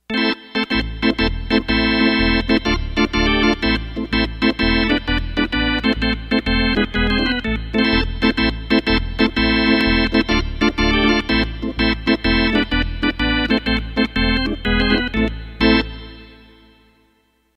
piano-bcb-10